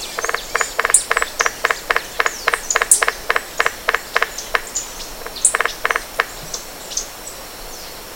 CARPINTERO OCRÁCEO